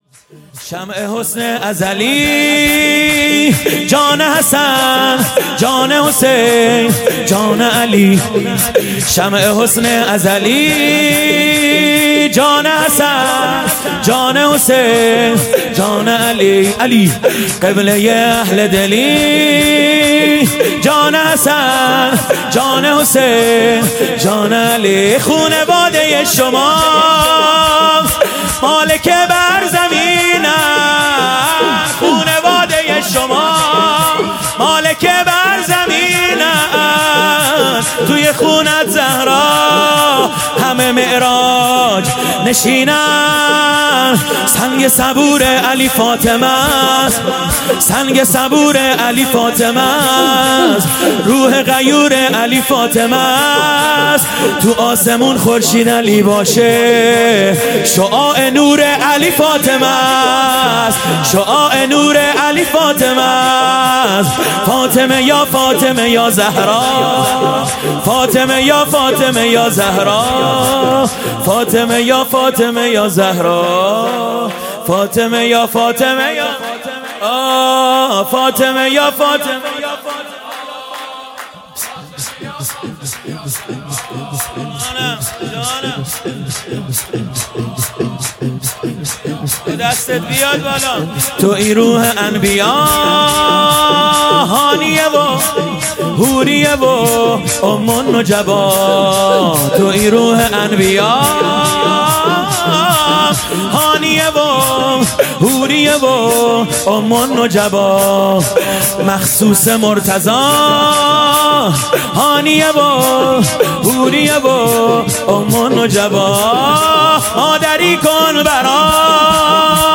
0 0 مولودی | شمع حسن ازلی
شب دوم جشن میلاد حضرت زهرا سلام الله علیها ۱۴۰۱